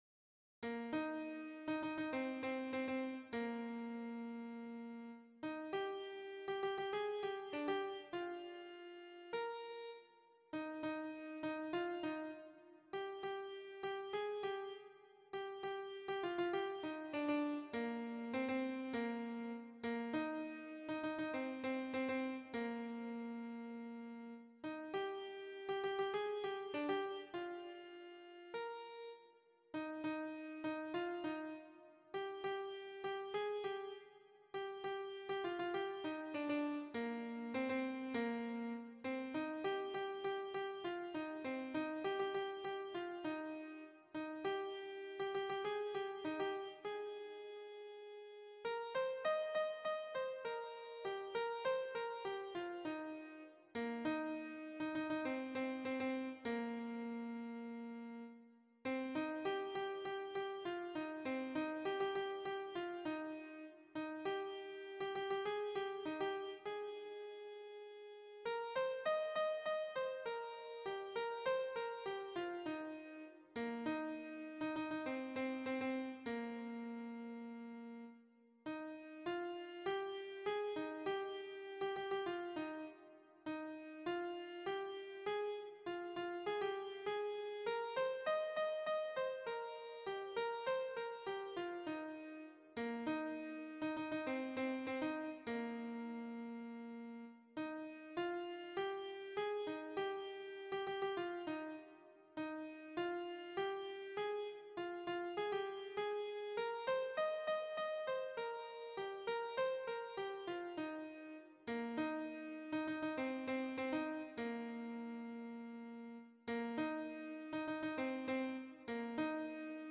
Répétition SATB4 par voix
Alto